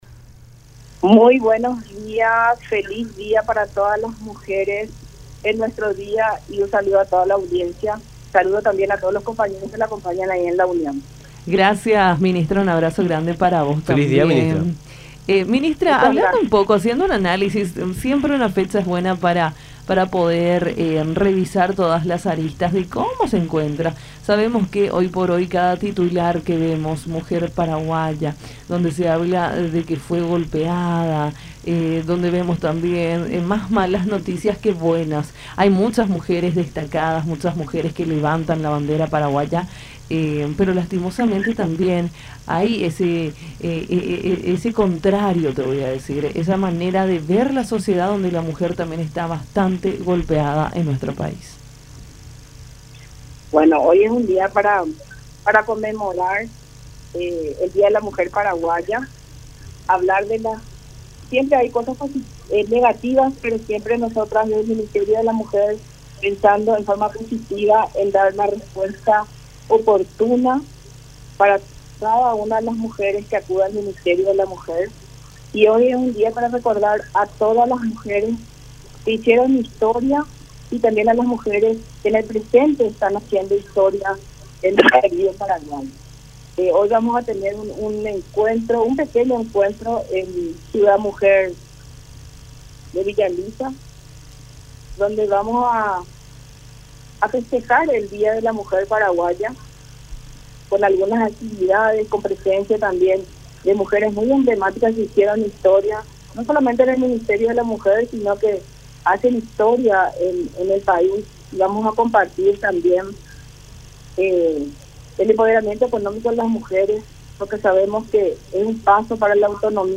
“Hoy es un día para conmemorar. Desde el ministerio siempre apoyamos por dar una respuesta oportuna a cada una de las mujeres que acuden acá. Es una fecha importante para recordar a aquellas mujeres que hicieron historia en Paraguay y las que aún, en el presente, lo siguen haciendo. El empoderamiento económico de la mujer es un paso importante para lograr esa independencia que siempre anheló”, dijo Celina Lezcano, ministra de la Mujer, en contacto con Nuestra Mañana por La Unión.